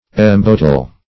embottle - definition of embottle - synonyms, pronunciation, spelling from Free Dictionary Search Result for " embottle" : The Collaborative International Dictionary of English v.0.48: Embottle \Em*bot"tle\, v. t. To bottle.